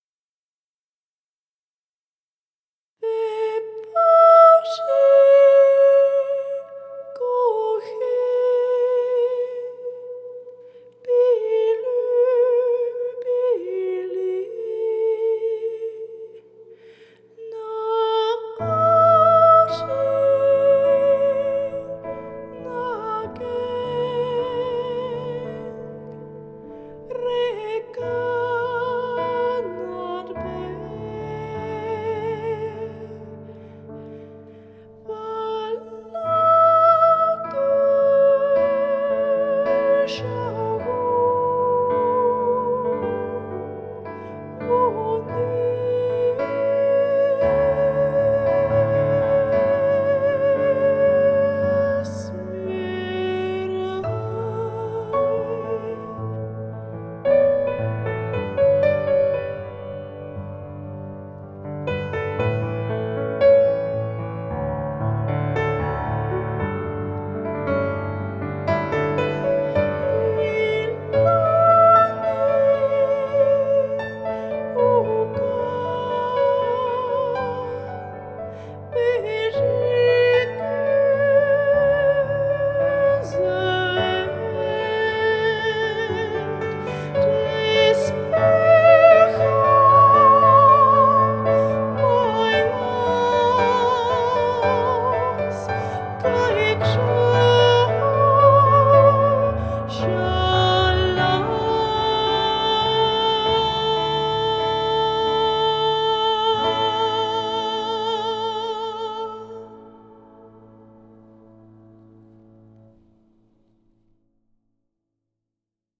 We also designed musical notation systems for each culture and the musical scales that they represent. We performed four songs, two of which can be heard/seen: